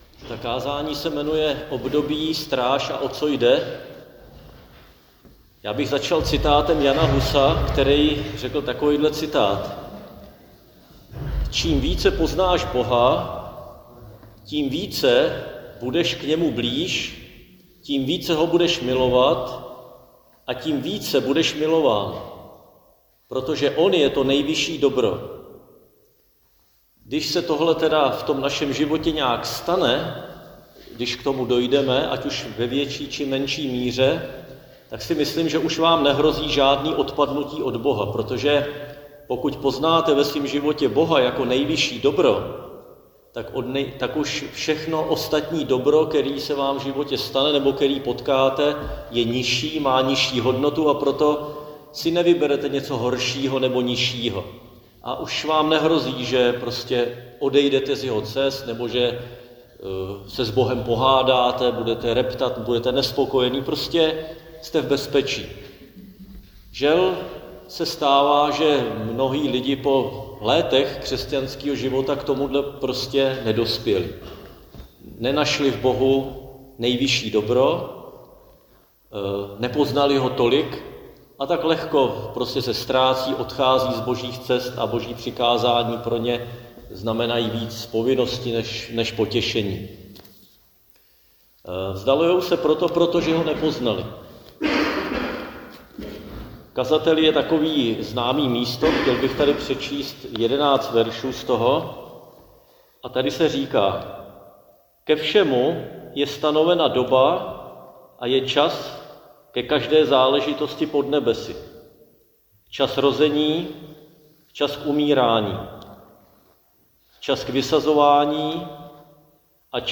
Křesťanské společenství Jičín - Kázání 29.3.2026